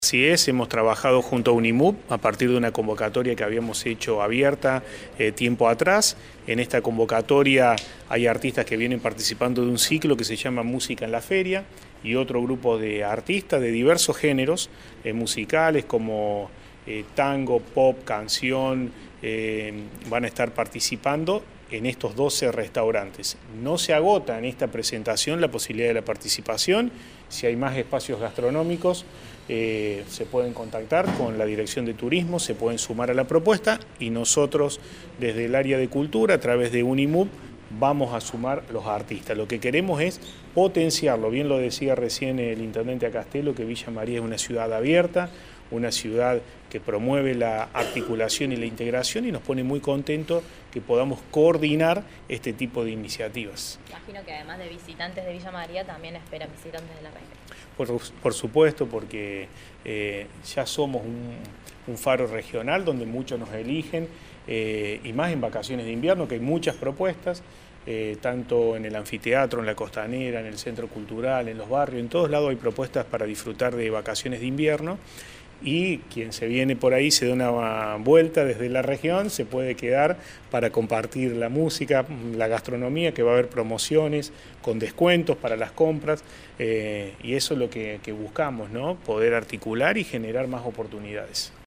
El secretario de Gobierno, Cultura y Relaciones Institucionales, Marcos Bovo brindó detalles de esta nueva iniciativa.